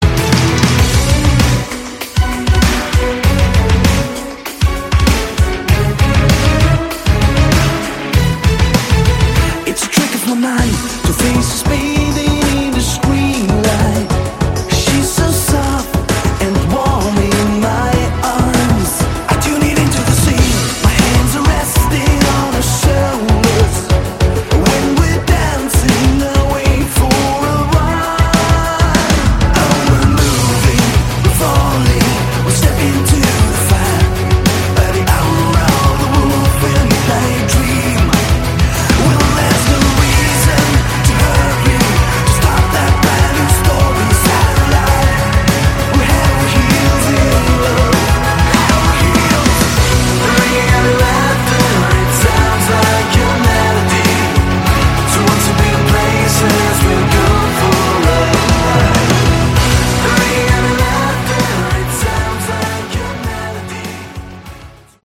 Category: Modern Synth Hard Rock